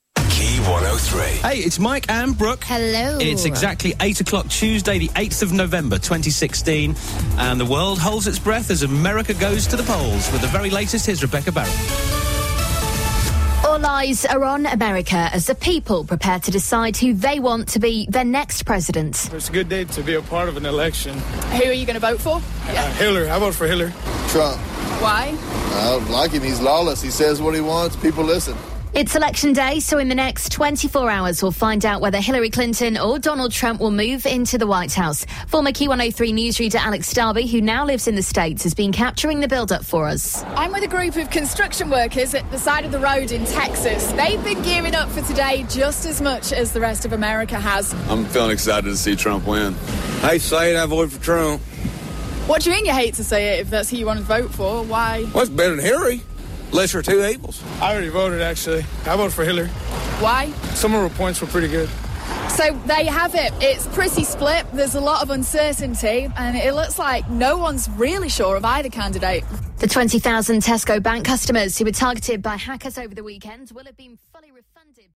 reporting from America